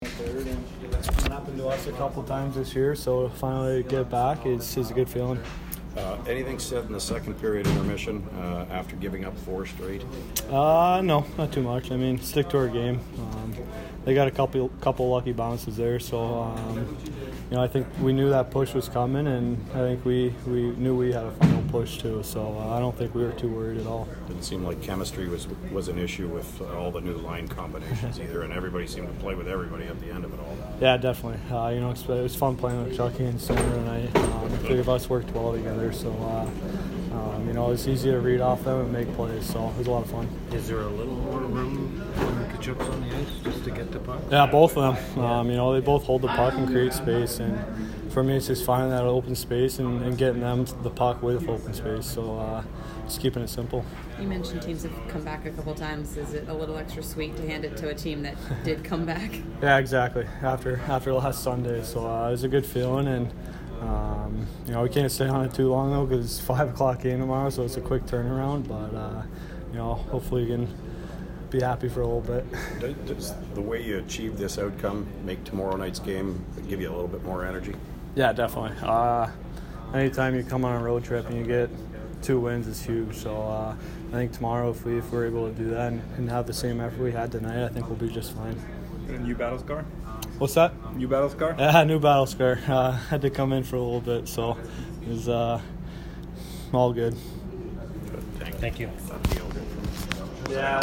post-game